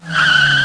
SKID